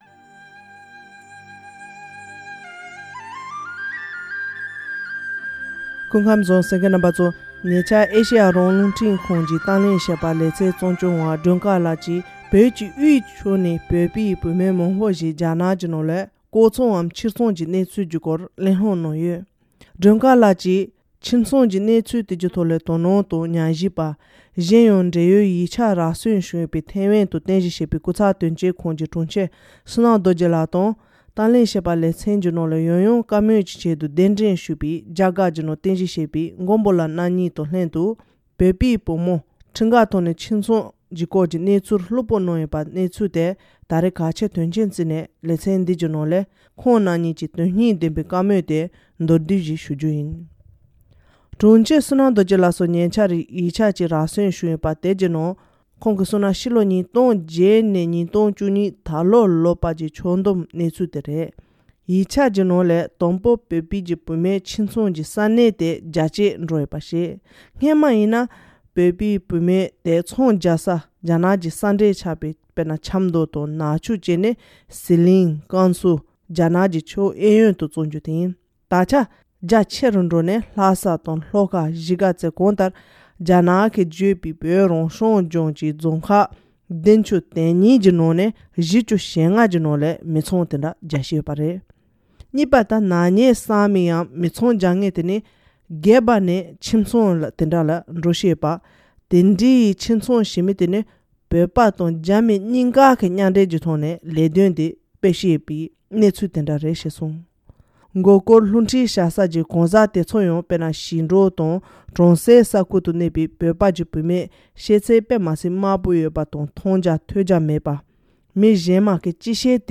བོད་ཀྱི་དབུས་ཕྱོགས་ནས་བོད་པའི་བུད་མེད་མང་པོ་རྒྱ་ནག་གི་ནང་ལྐོག་ཚོང་ངམ་ཕྱིར་ཚོང་བྱེད་ཀྱི་ཡོད་པའི་ཐད་ལ་ང་ཚོ་རླུང་འཕྲིན་ཁང་གི་གཏམ་གླེང་ཞལ་དཔར་གྱི་ལེ་ཚན་ནང་གླེང་མོལ་གནང་ལས་ཟུར་འདོན།